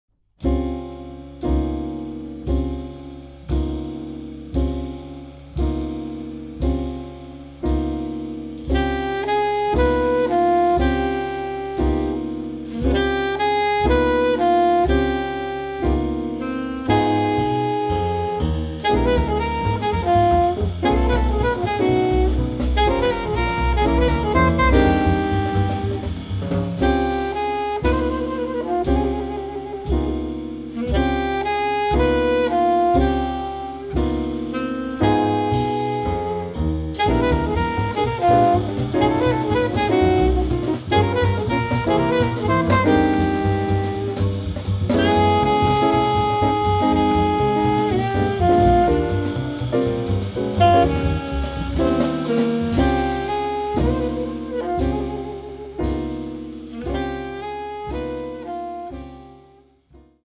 sax
piano
bass
drums